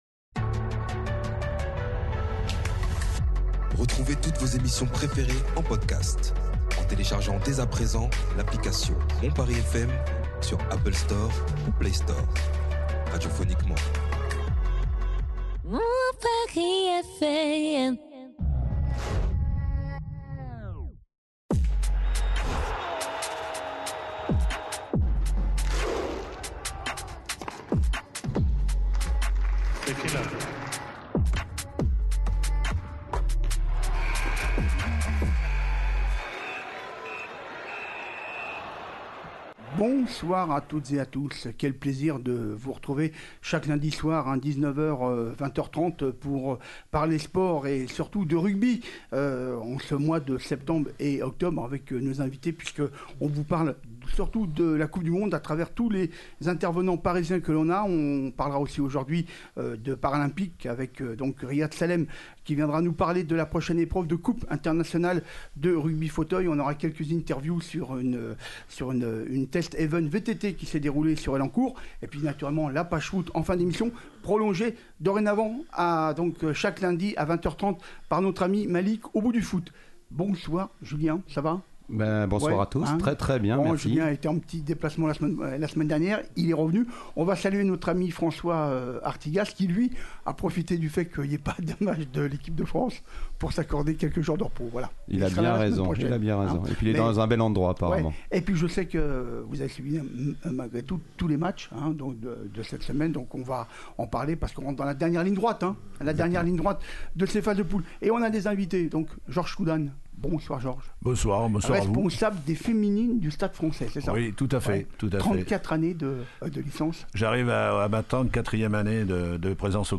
Enfin , comme d'habitude , nous finirons cette émission par les débats enflammés de nos spécialistes sur l'actualité football de la semaine.